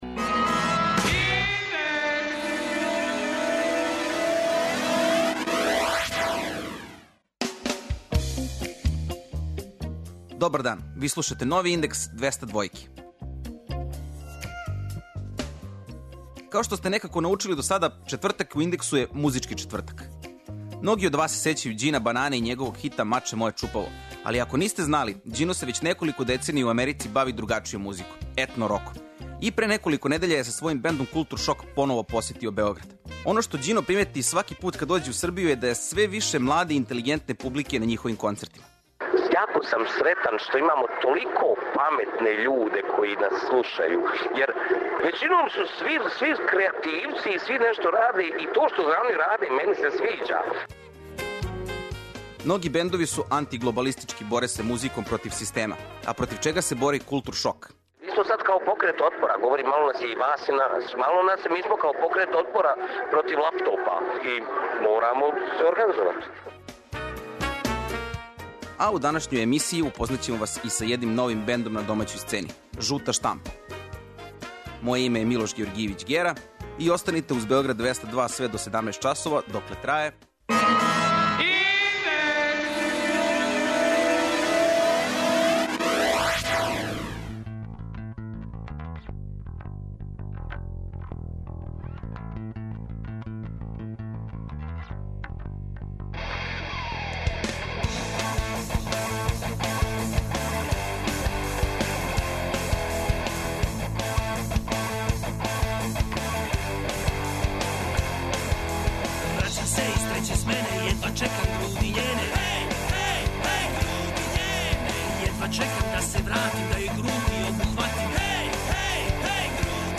''Индекс 202'' је динамична студентска емисија коју реализују најмлађи новинари Двестадвојке.